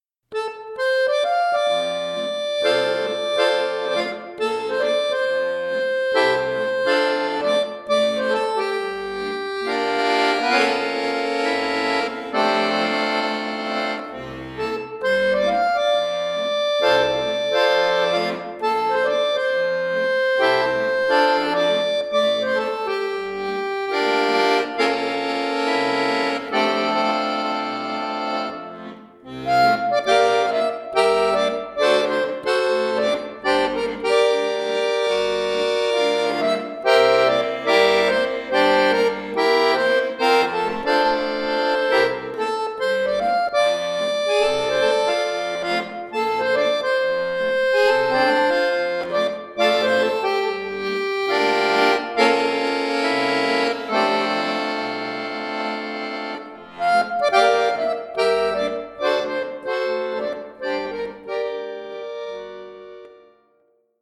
Swing